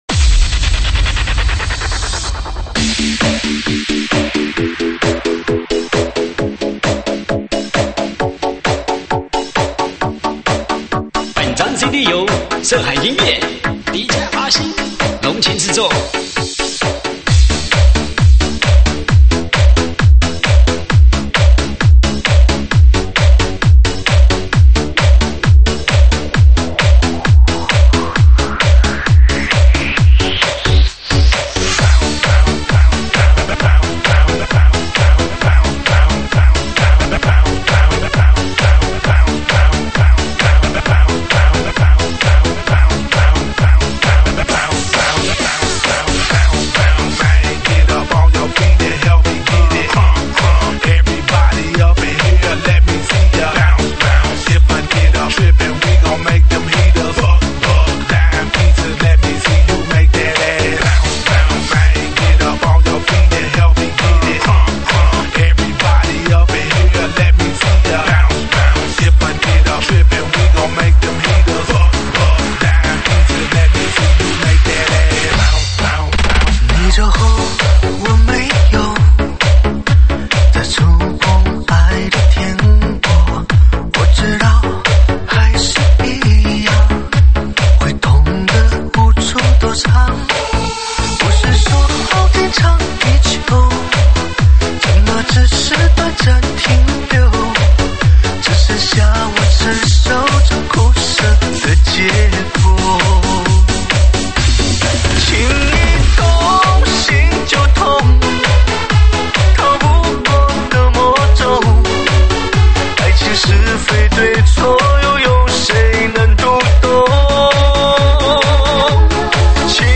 伤感情歌